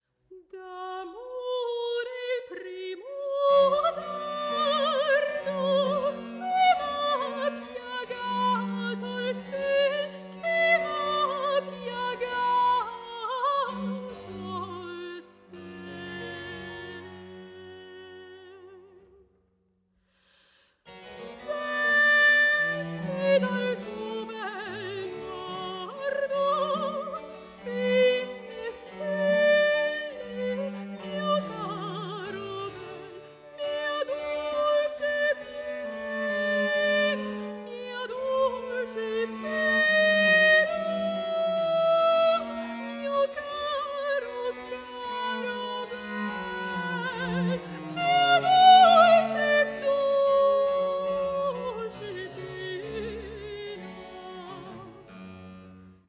soprano
cello
harpsichord
16 Aria